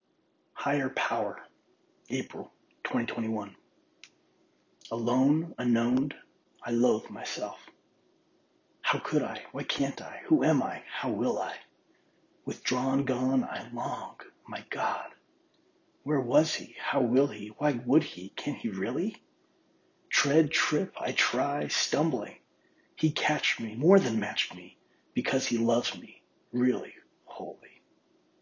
poem-higher-power.mp3